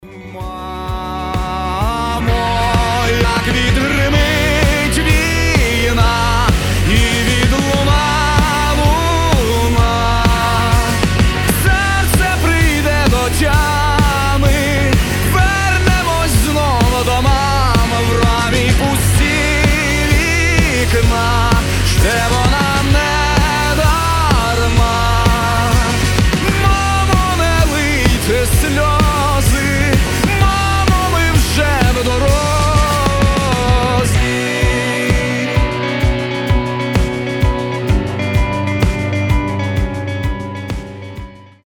мужской вокал
громкие
украинский рок